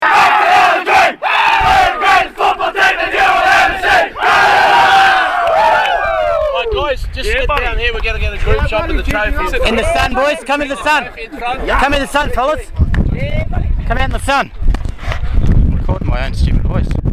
Kasey Badger and the Championship Final Ref's (Photo's : OurFootyMedia) Newcastle KNIGHTS - 2011 National U18 Club & SG Ball Cup Champions (Photo's : OurFootyMedia) KNIGHTS SING VICTORY SONG
newcastle_knights_sing_austClubChamps_01.mp3